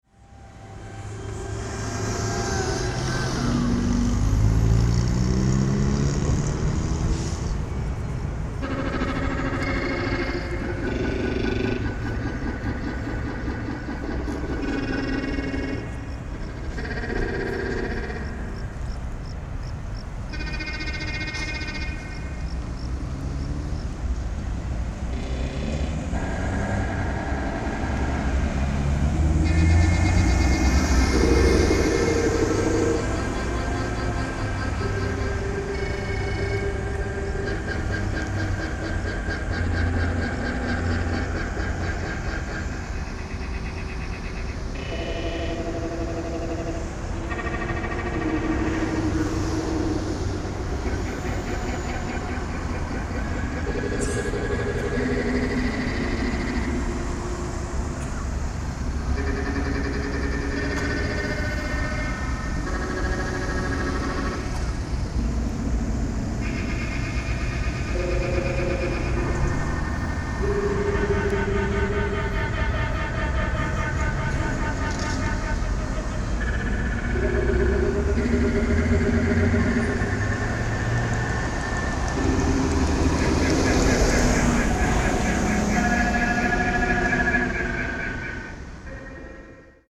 Through the north side of the Tower, recordings from the ambulant vendors talked with the Main Train Terminal. Facing south towards the Sheraton Hotel, recordings from the police scanners were heard.
Finally, recordings from musicians performing in the streets were heard through the south side.
Site: Torre Monumental, Retiro Square.